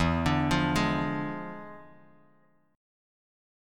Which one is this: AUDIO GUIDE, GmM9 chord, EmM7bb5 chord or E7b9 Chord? E7b9 Chord